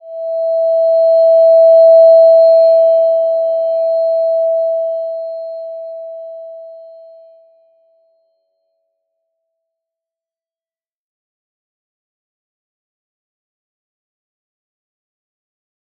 Slow-Distant-Chime-E5-mf.wav